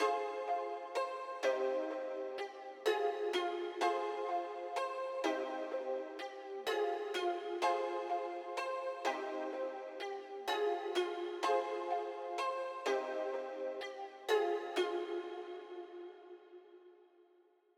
Souf 126 Pluck.wav